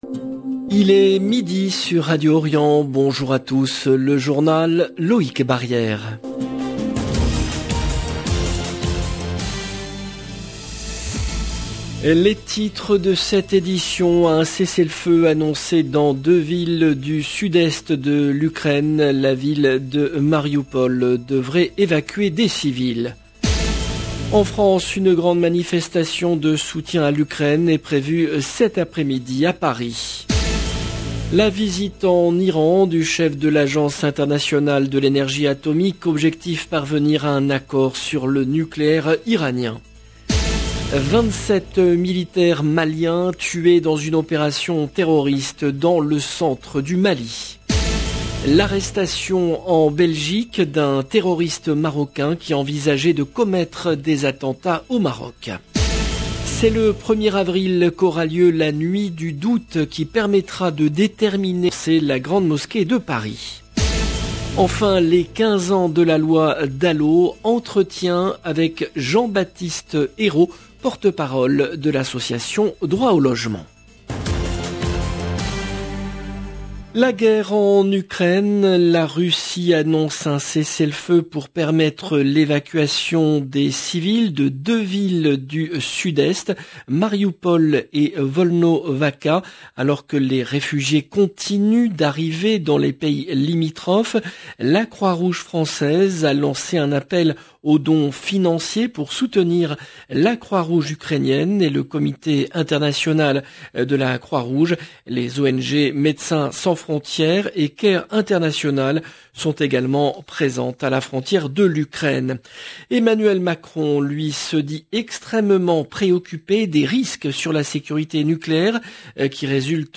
LE JOURNAL EN LANGUE FRANCAISE DE MIDI DU 5/03/22